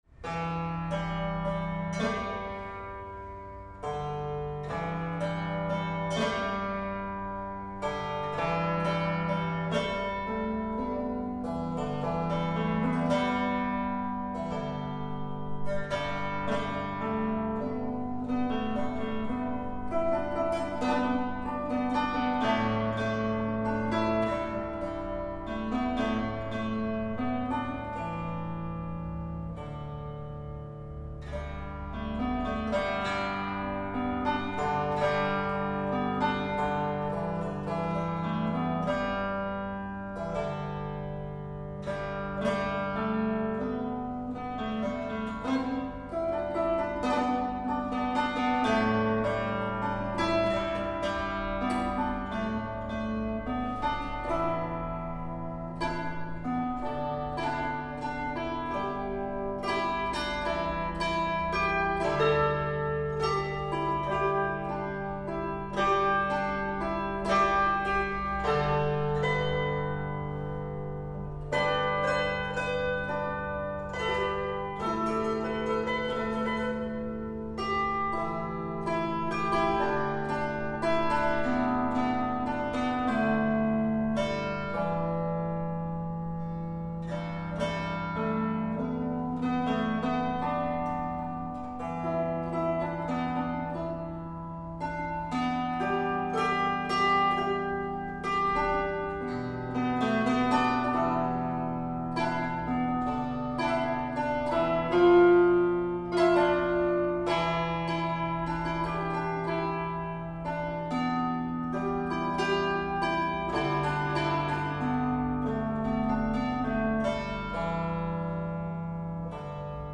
with its idiomatic clàrsach style and harmonies
Early Gaelic Harp Info